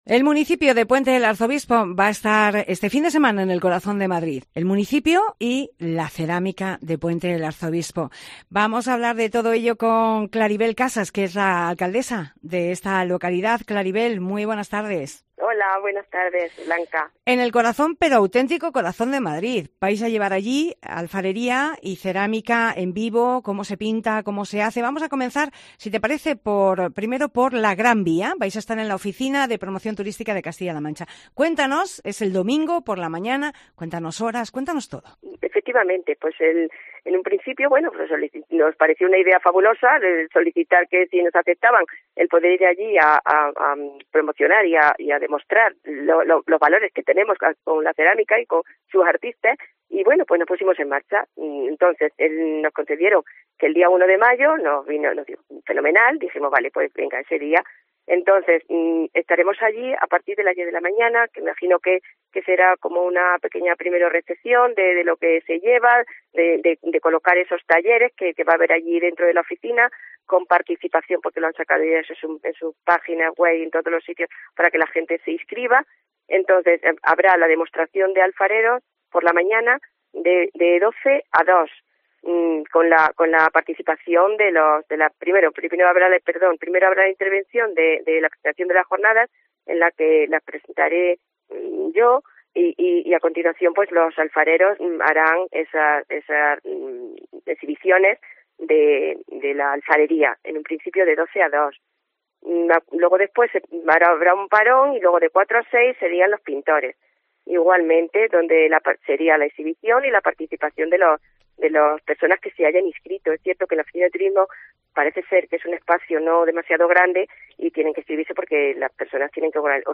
Entrevista a Claribel Casas, alcaldesa de El Puente del Arzobispo